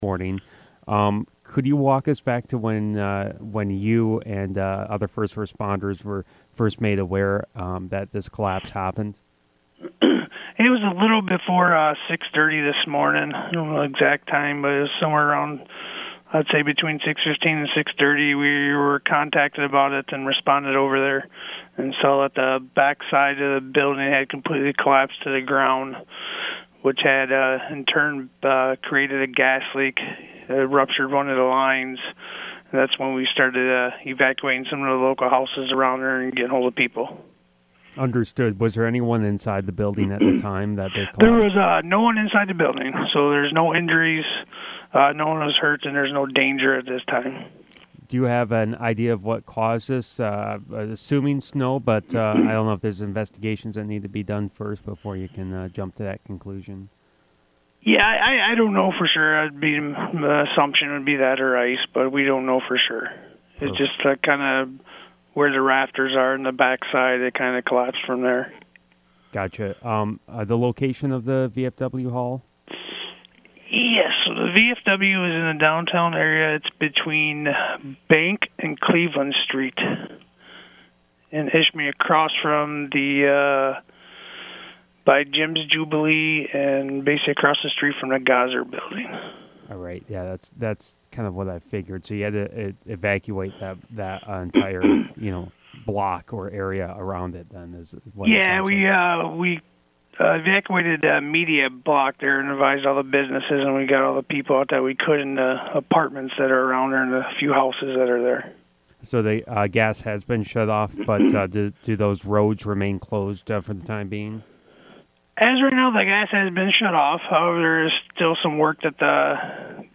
INTERVIEW WITH ISHPEMING POLICE CHIEF
ishpeming-chief.mp3